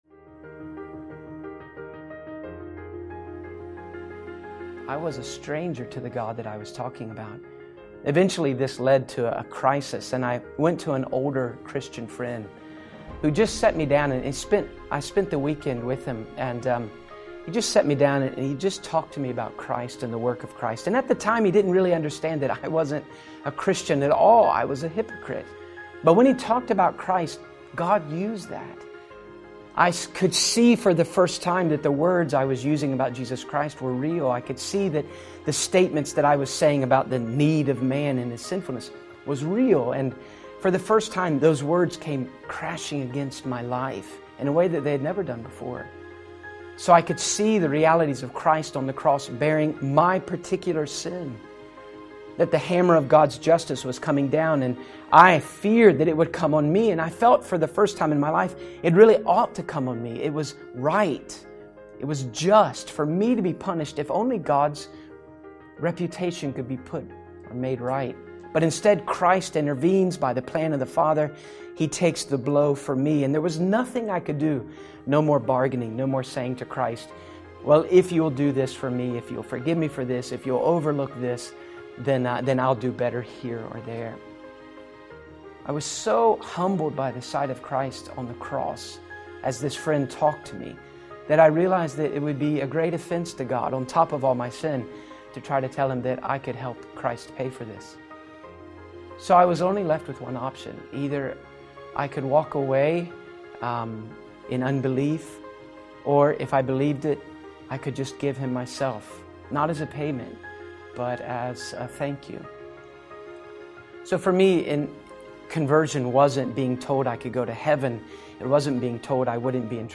Life Story & Testimony